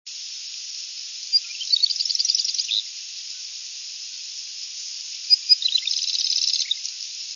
River Drive South, Sayerville, same bird, different variation on song, 5/5/03 (29kb)
wren_marsh_746.wav